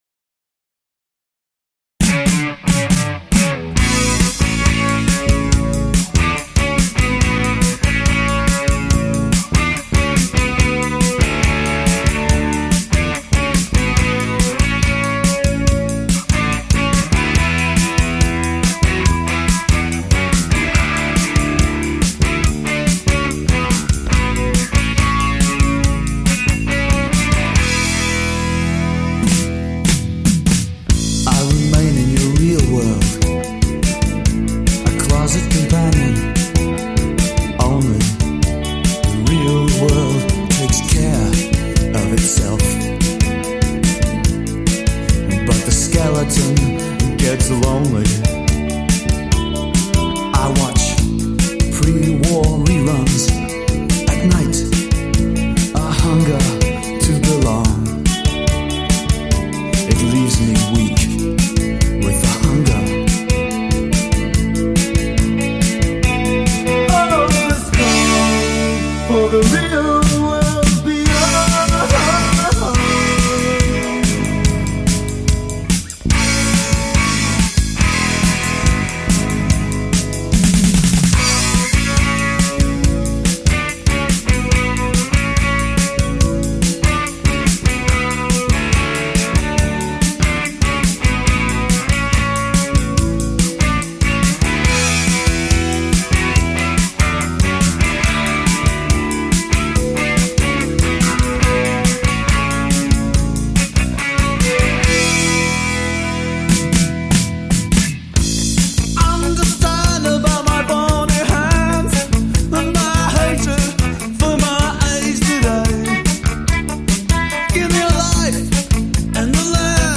bass
drums
lead vocals/guitar
These two songs also feature guest backing vocals